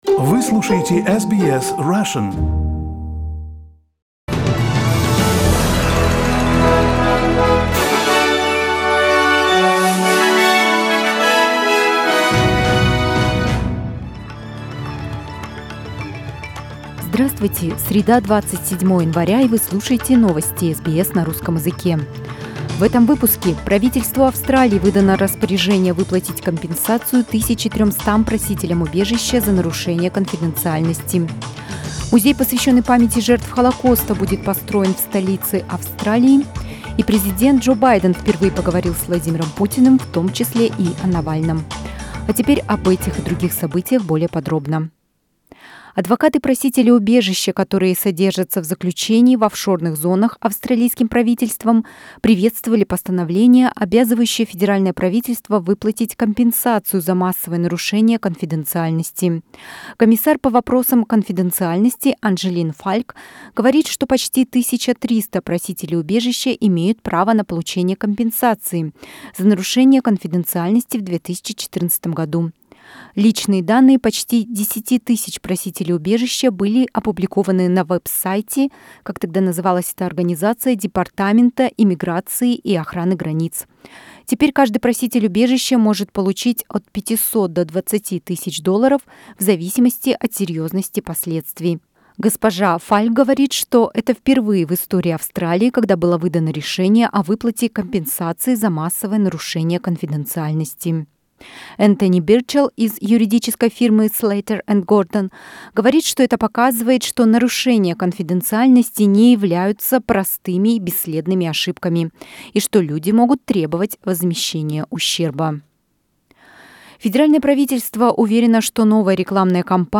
Новостной выпуск за 27 января